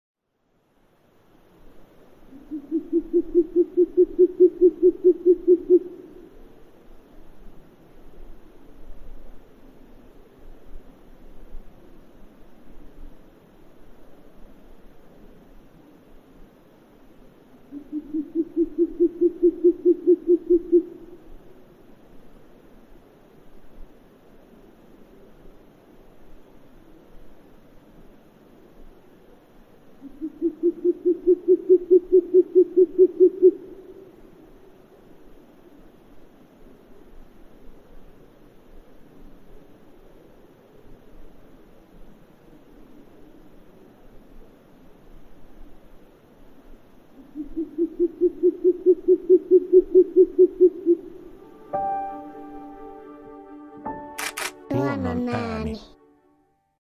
Kuuntele: Suopöllö
Suopöllö on sarvipöllön ohella ainoa muuttava pöllömme. Suopöllön soidinääni hitaasti puuskuttava, 10–20-tavuinen ”pu pu pu…”, johon naaras vastaa usein rääkäisevällä ”khreäh”-äänellä.